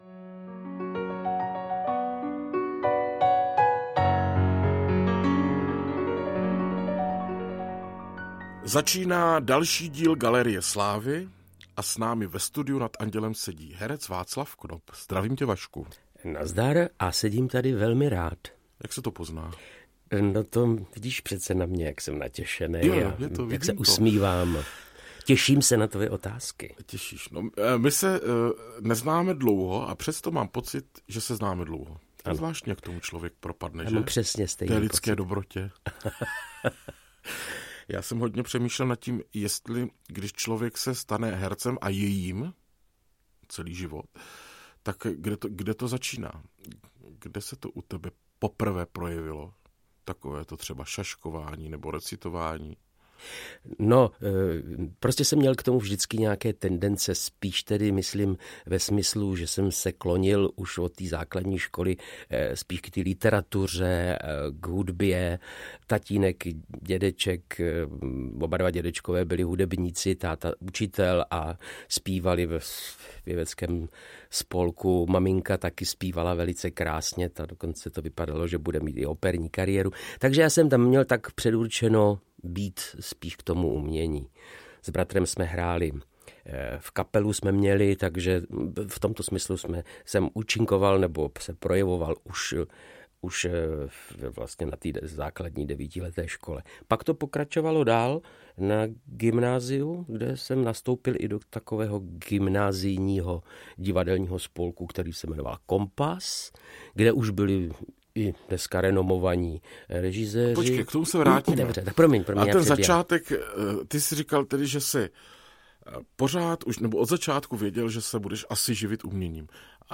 obsahuje biografický rozhovor se známou osobností
Ukázka z knihy